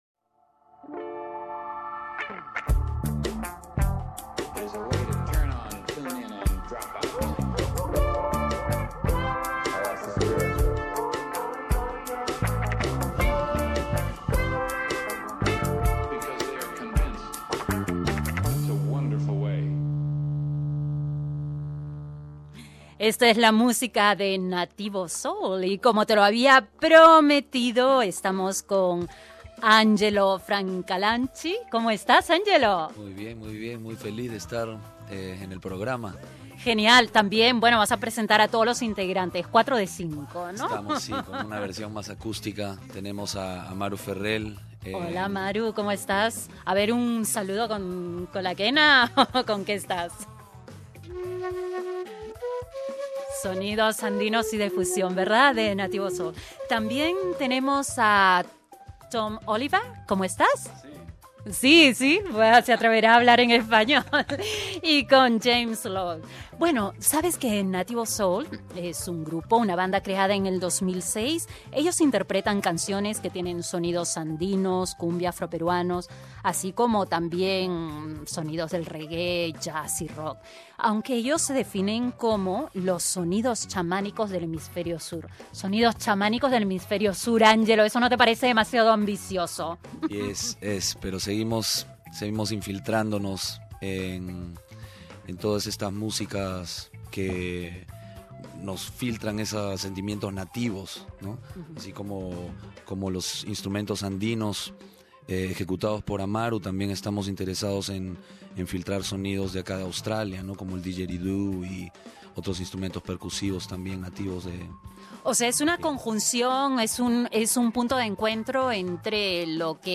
Nativo Soul, una banda creada en 2006, va creciendo como la espuma en Australia y encandila a sus públicos con sus ritmos de fusión de jazz, reggae, rock con los andinos, afroperuanos y aborigenes.
Escucha sus canciones y la entrevista con Nativo Soul Share